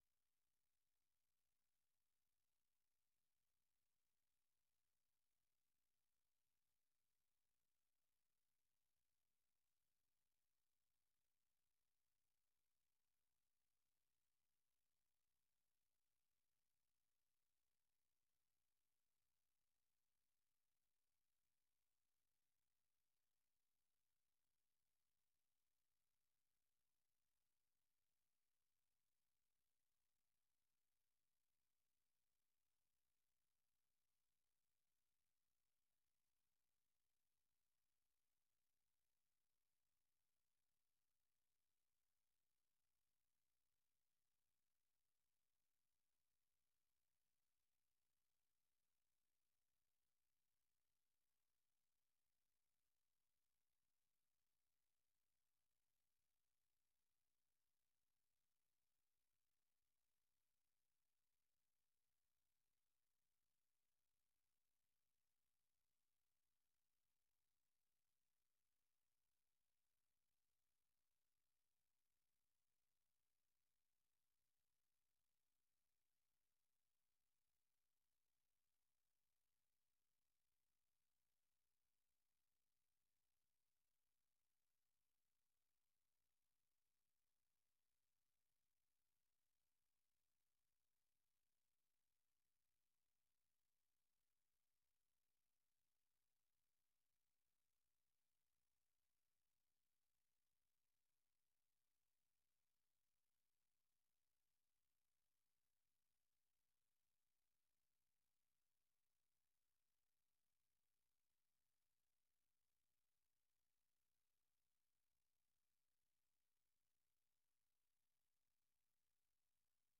Listen Live - 粵語廣播 - 美國之音